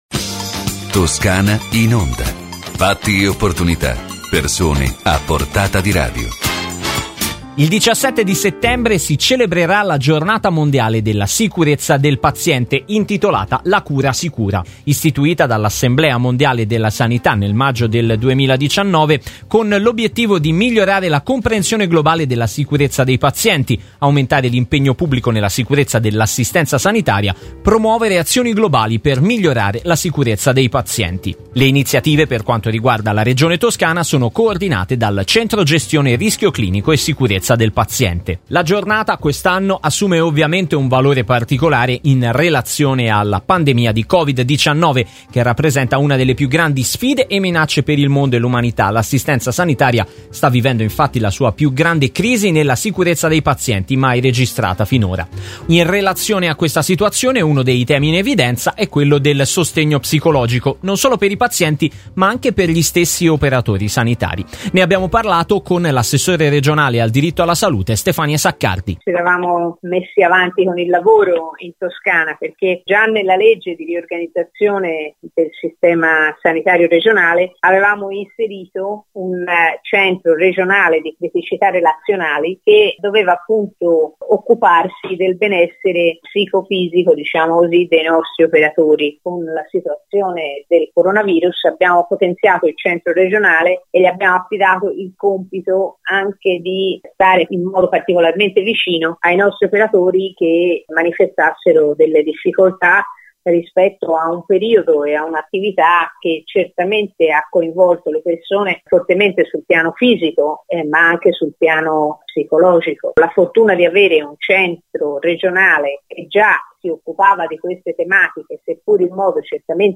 Le interviste radiofoniche
• Stefania Saccardi - Assessore alla Salute della Regione Toscana